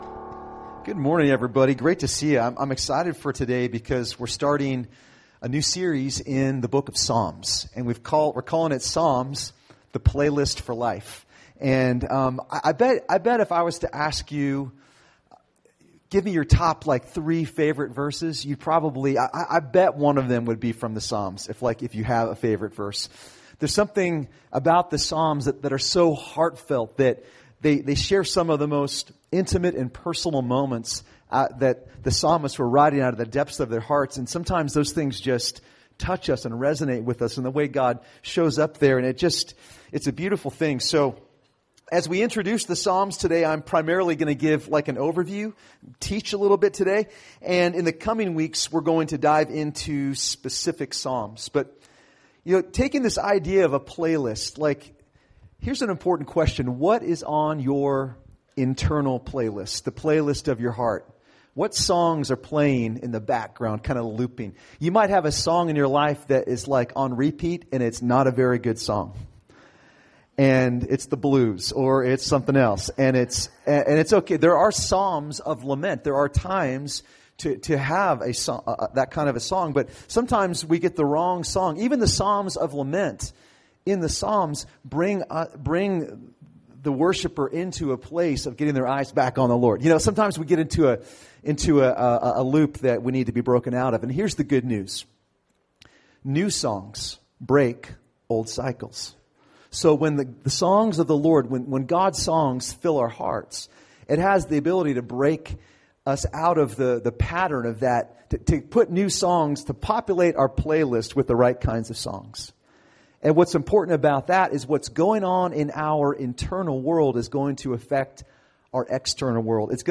Recorded at New Life Christian Center, Sunday, September 9, 2018 at 9 AM.